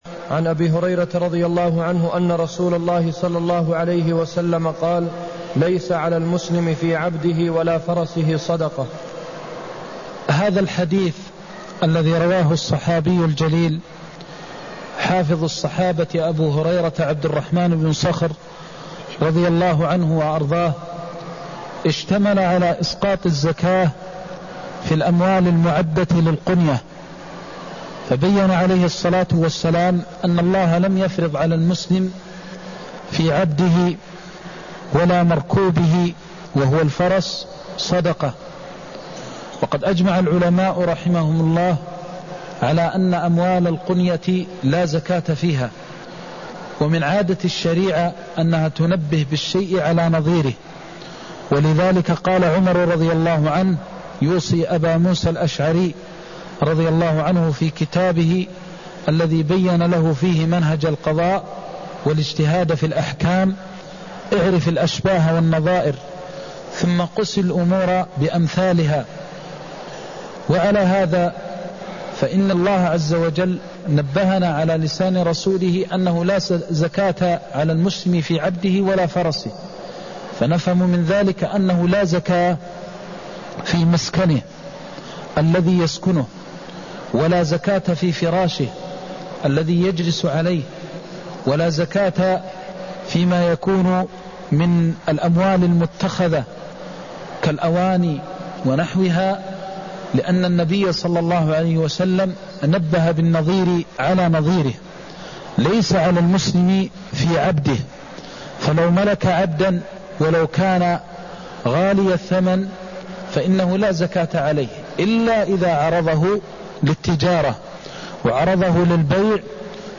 المكان: المسجد النبوي الشيخ: فضيلة الشيخ د. محمد بن محمد المختار فضيلة الشيخ د. محمد بن محمد المختار ليس على المسلم في عبده ولا فرسه صدقة (164)